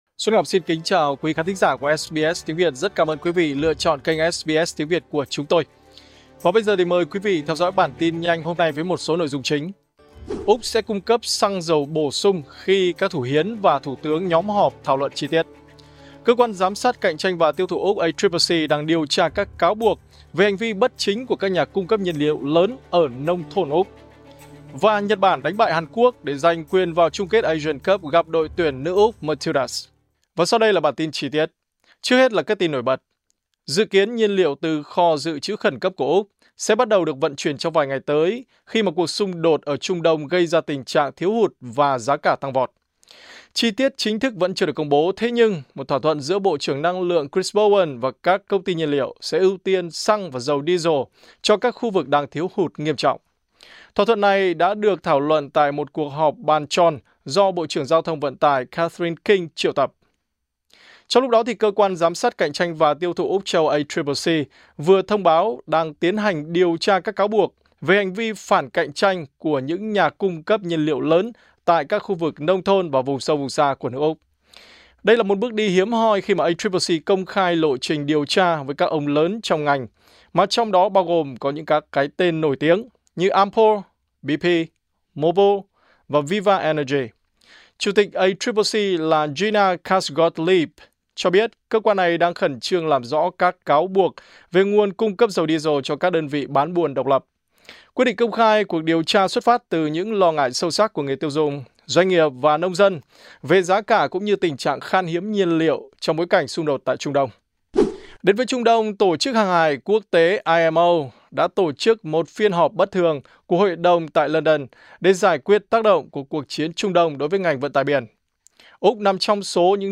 Vietnamese news bulletin